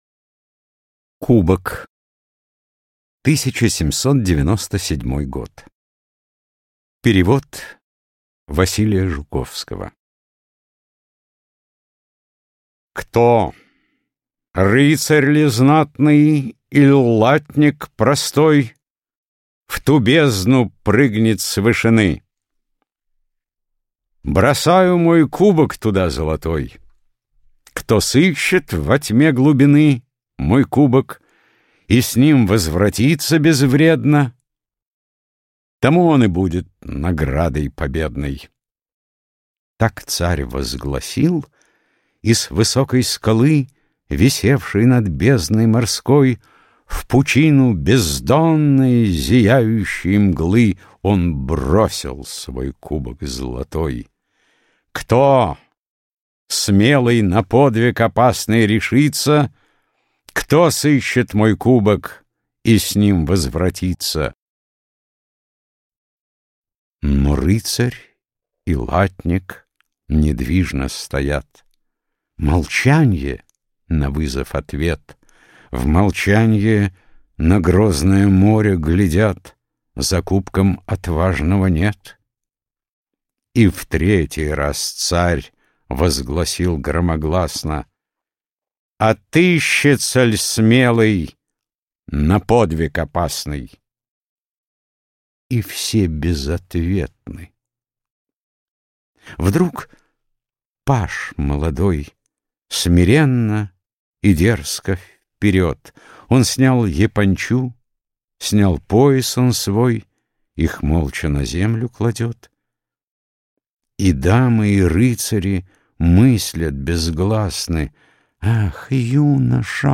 Аудиокнига Поэзия | Библиотека аудиокниг